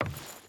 Wood Chain Walk 5.wav